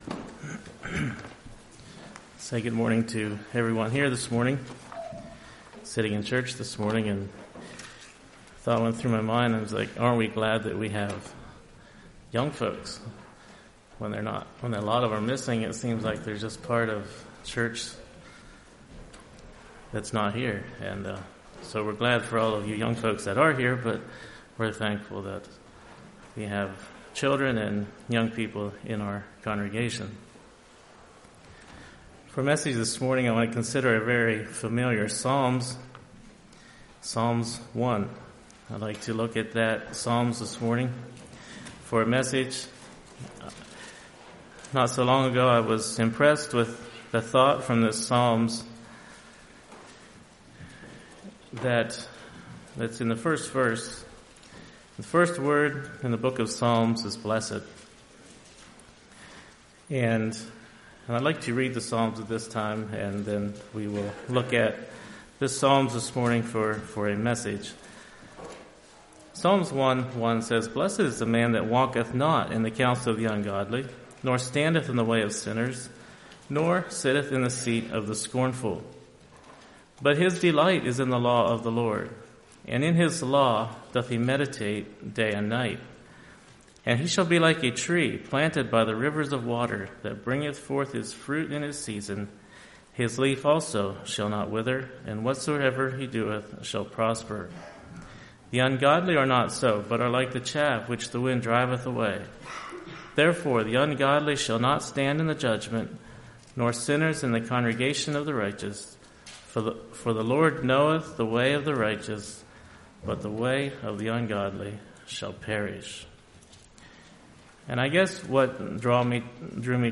Sermons 02.25.23 Play Now Download to Device Psalm 1 Congregation